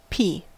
Ääntäminen
IPA : /ˈpiː/